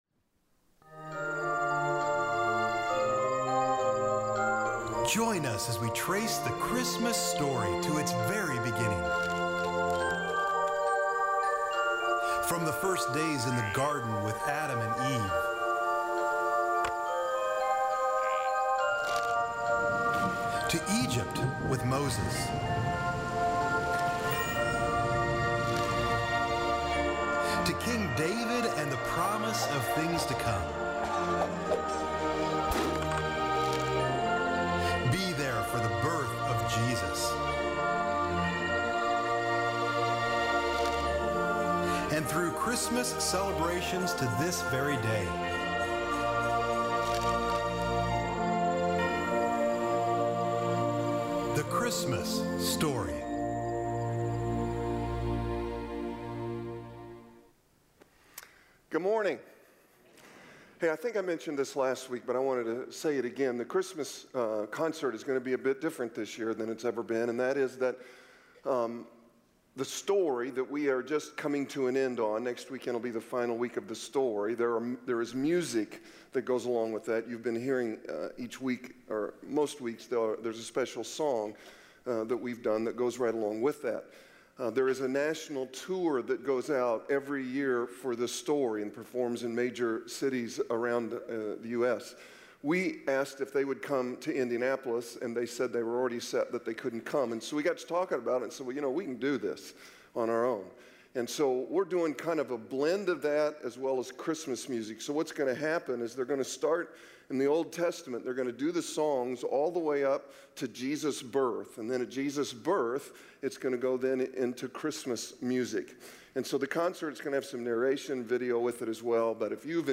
Paul's Final Days | Northview Church | Northview Church